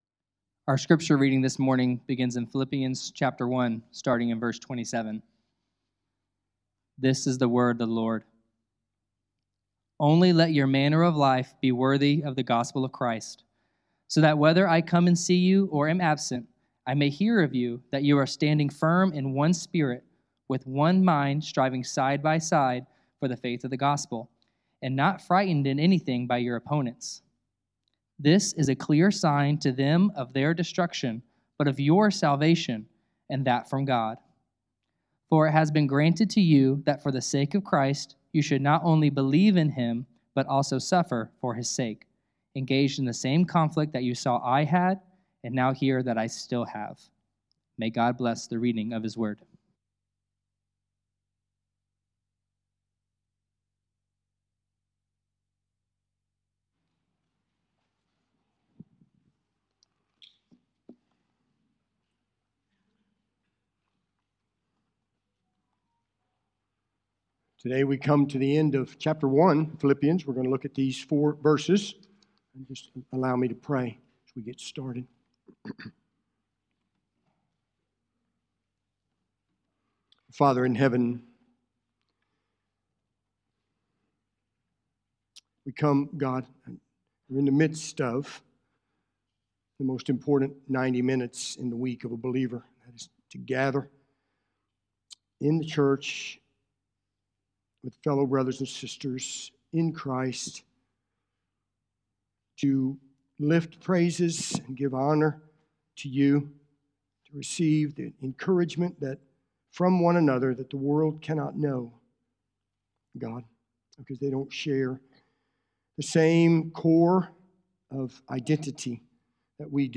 A sermon from the book of Philippians from Oak Park Baptist Church in Jeffersonville, Indiana